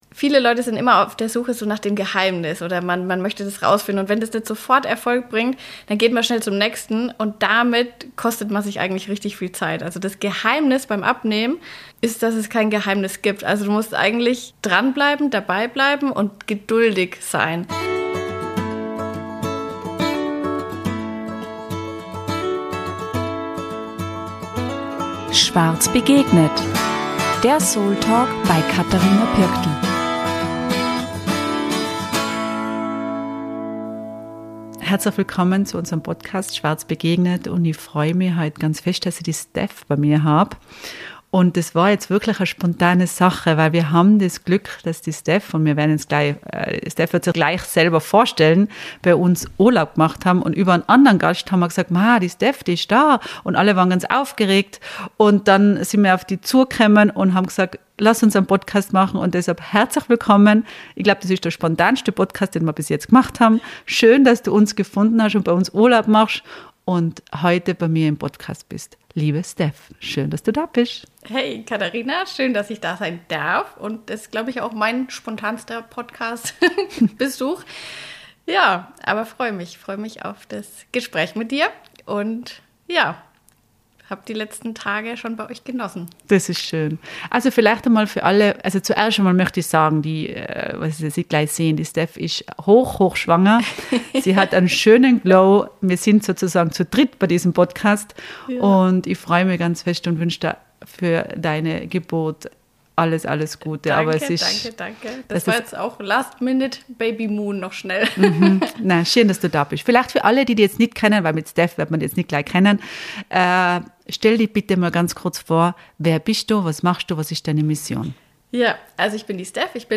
Seitdem lebt sie vor, wie Ernährung, Bewegung und Alltagshacks miteinander verschmelzen können, ohne dass Genuss oder Lebensfreude auf der Strecke bleiben. Ein Gespräch über: